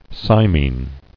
[cy·mene]